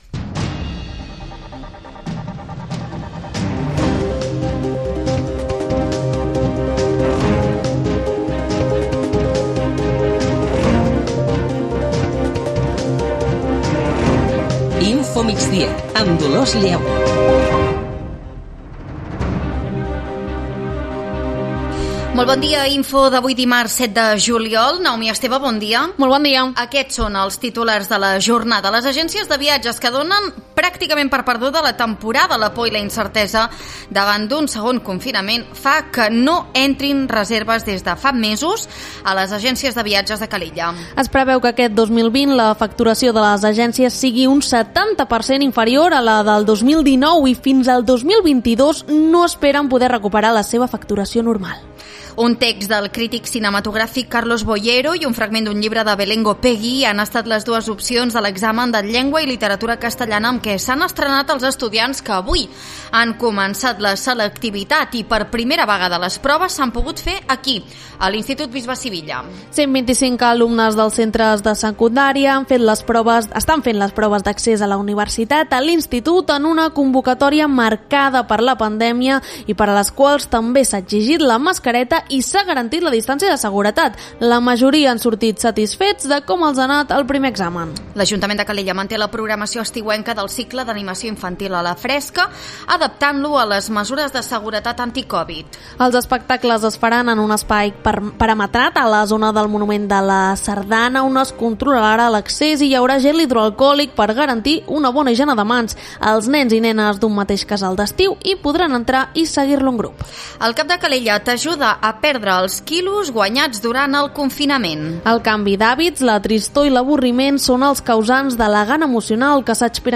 Notícies locals i comarcals.